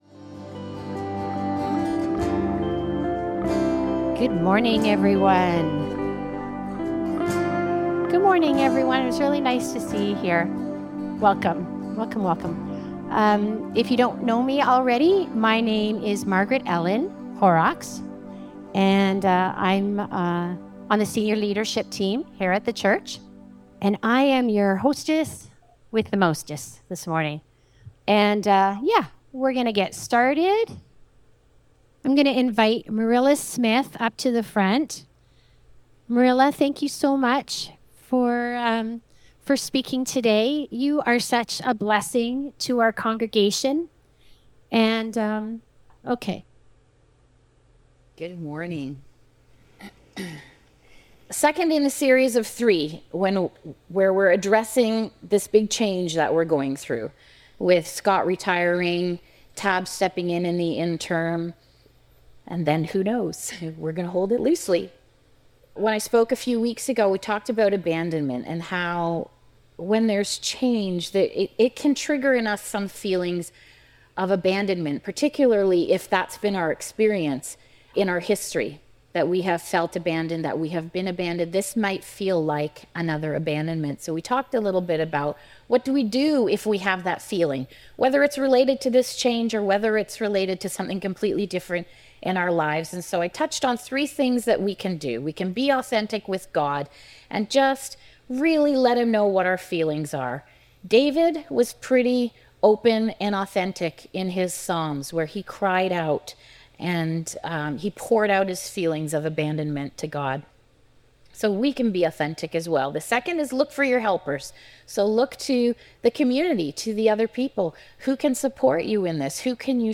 Series: Guest Speaker Service Type: Sunday Morning Change inevitably brings loss.